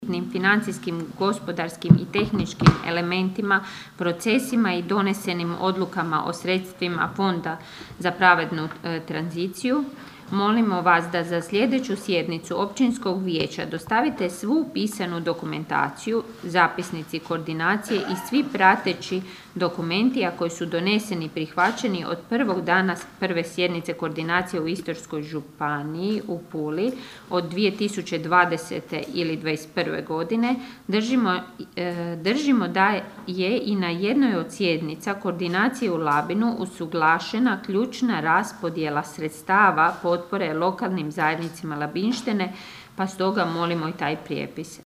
U pismu, koje je pročitala načelnica Ana Vuksan, vijećnici traže precizne odgovore o svim pitanjima vezanim za Fond: (